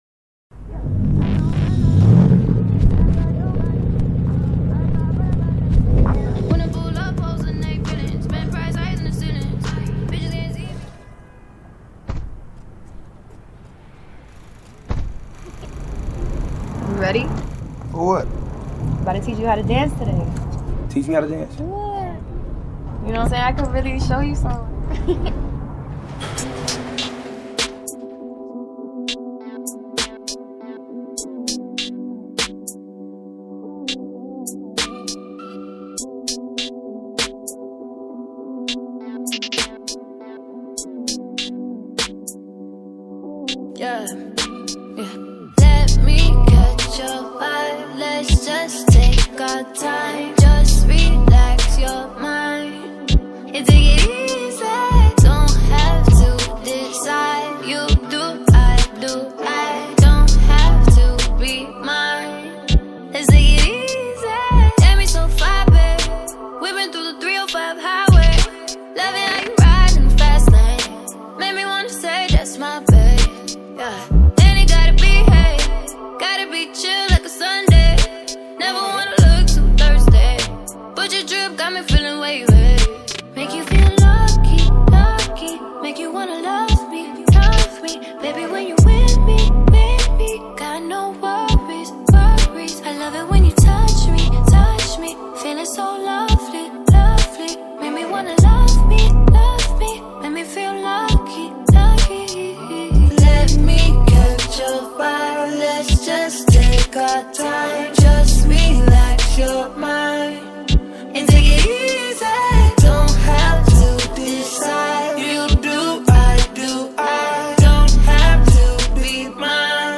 smooth and sultry R&B track